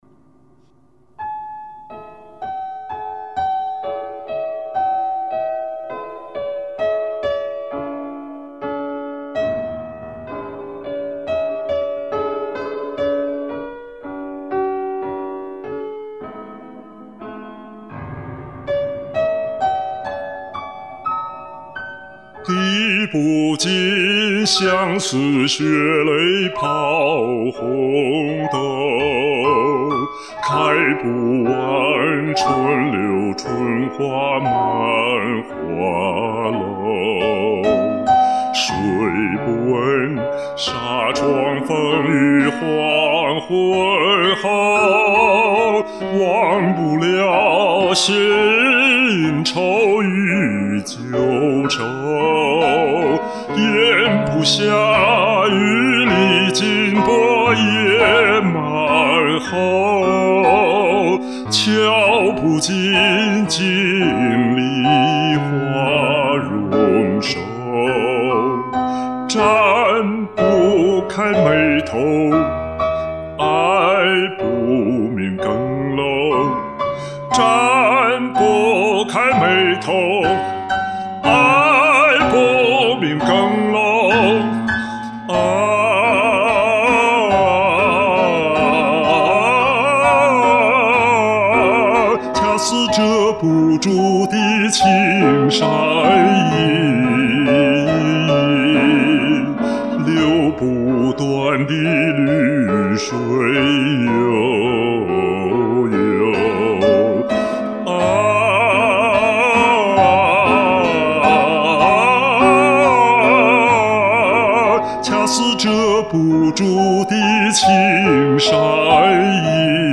1.《红豆词》是一首优秀的中国艺术歌曲。